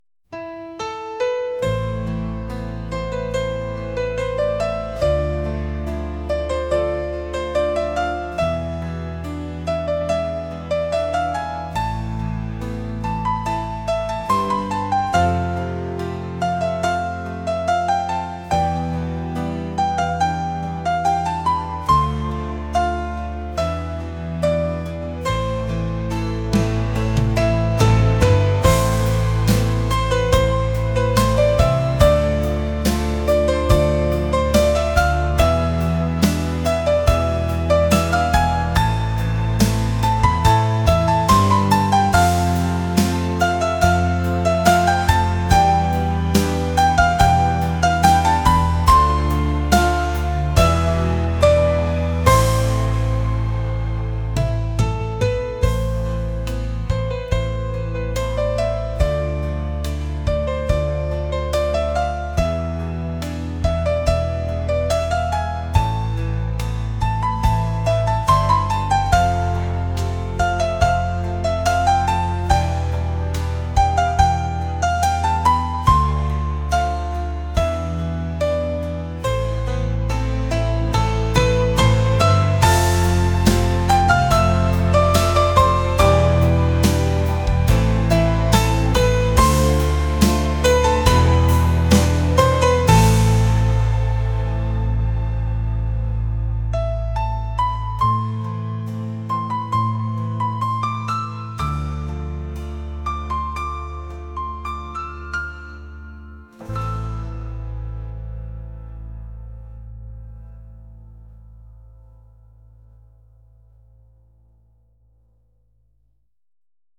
pop | acoustic | soul & rnb